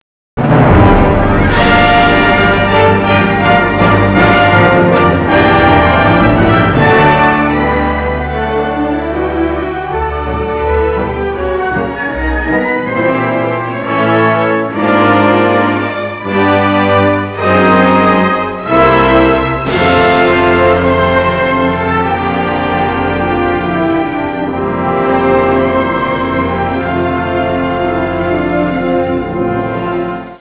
Colonna sonora
Original track music: